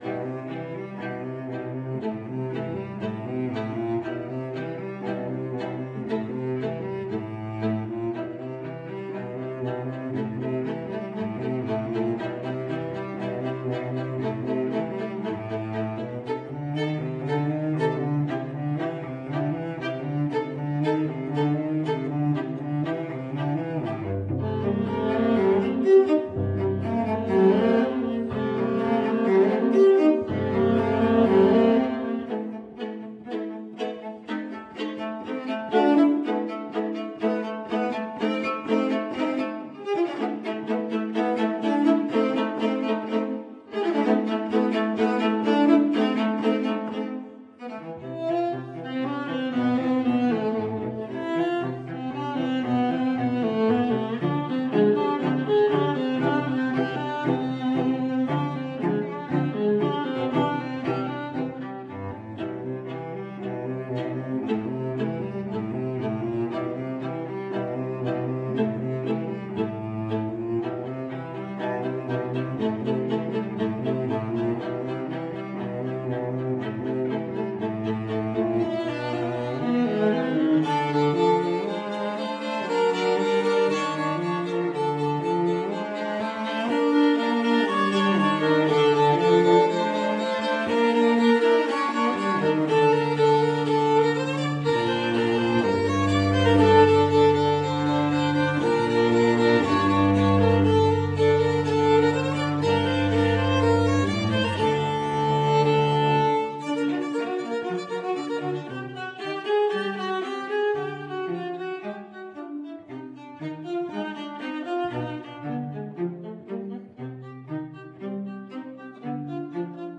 Outre mon travail sur les sons de la nature, je compose aussi pour des musiciens et des instruments bien réels - essentiellement de la musique de chambre: cordes, piano, vents, et bien-sûr la guitare qui est mon instrument "maternel"...
violoncelle
alto